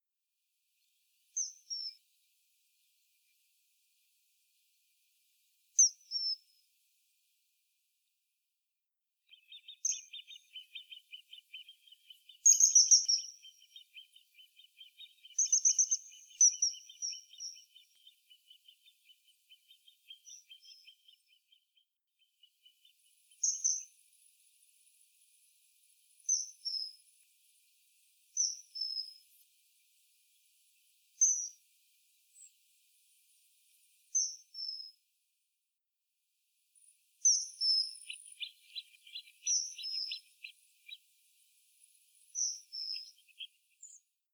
Martin pescatore
(Alcedo attis)
Martin-pescatore-Alcedo-atthis.mp3